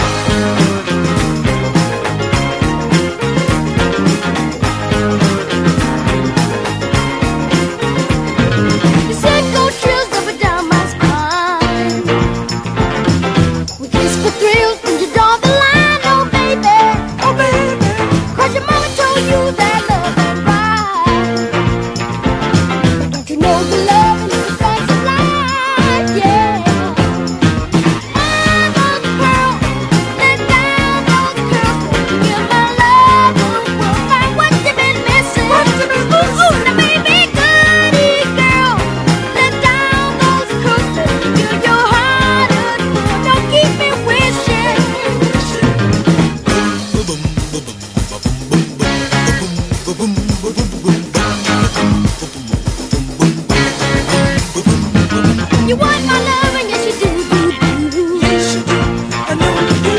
SOUL / RARE GROOVE/FUNK / NORTHERN SOUL / MODERN SOUL
メロウで温かみのあるスウィート・ソウル・バラード